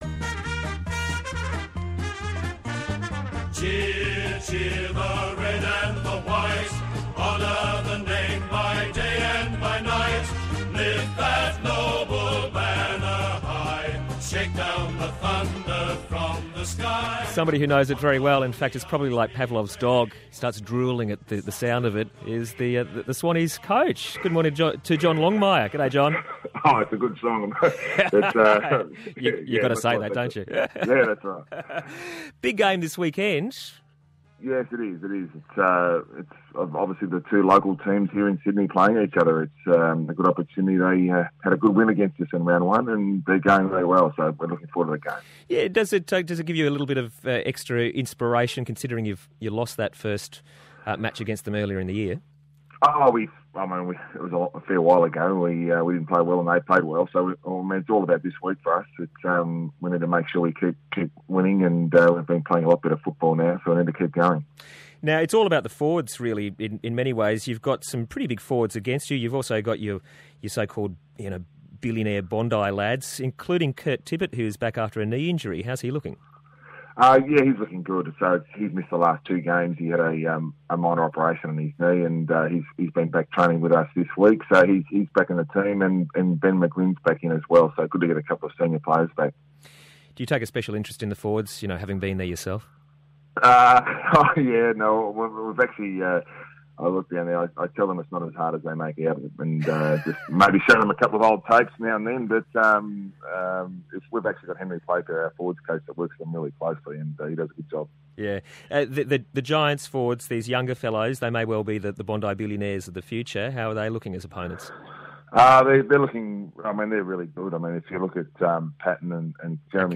Sydney Swans coach John Longmire on Breakfast with Robbie Buck on 702 ABC Sydney on Friday June 27, 2014